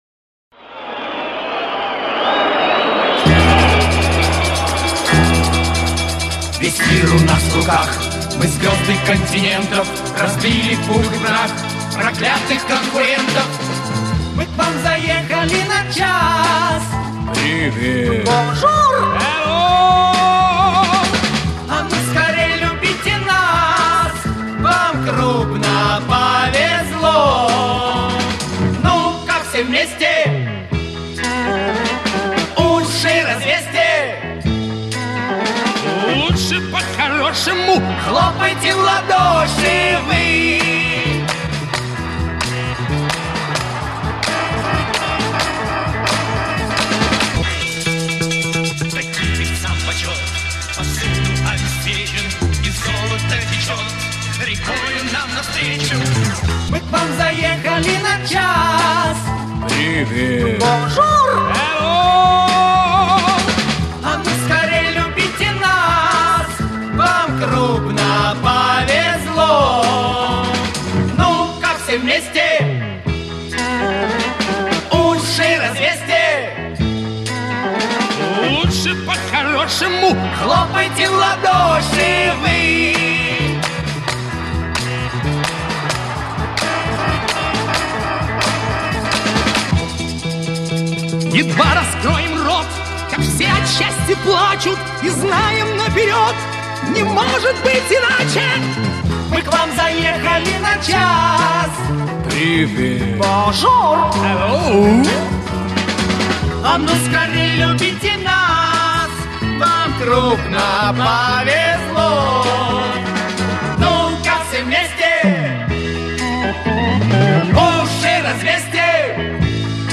Слышно вставки.  К тому же, она записана с шумом толпы.